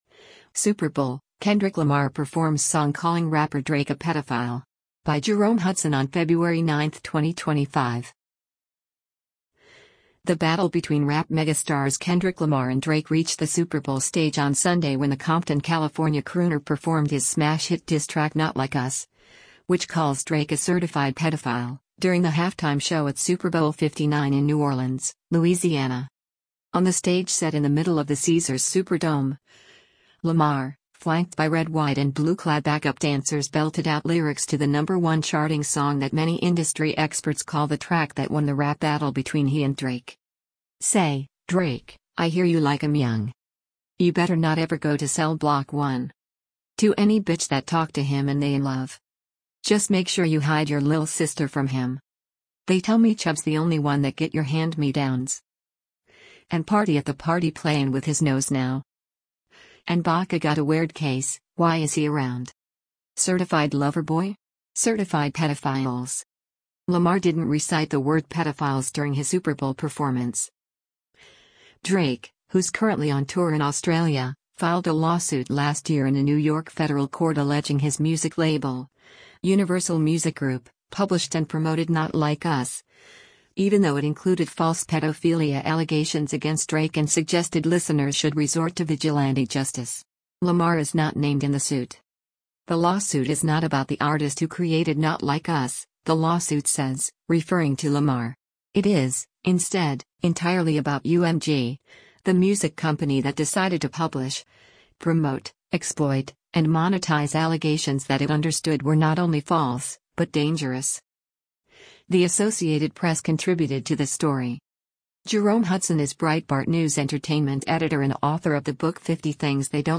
perform onstage
diss track